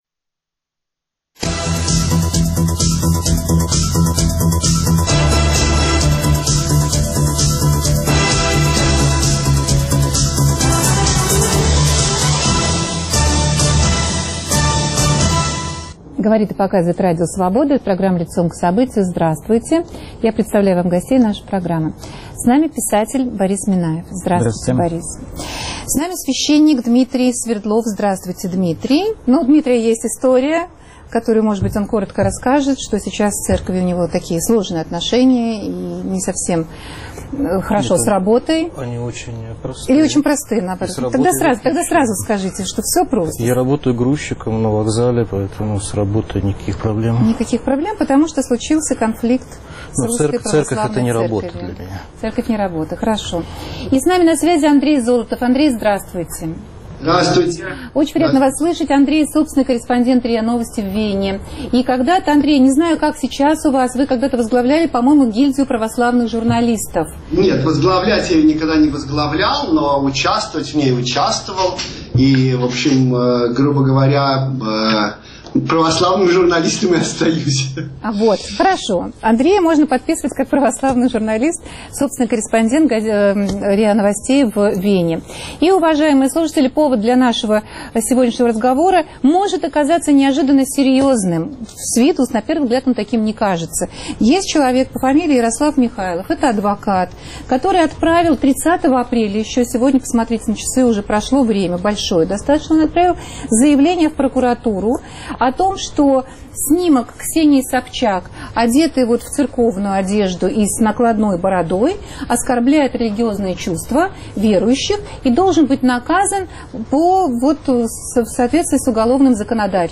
Оскорбление чувств верующих и разгул мракобесия и репрессий - где грань? Оскорбляет ли ваши чувства, если вы верующий, снимок Ксении Собчак, за который ее требуют привлечь к уголовной ответственности? Обсуждают журналисты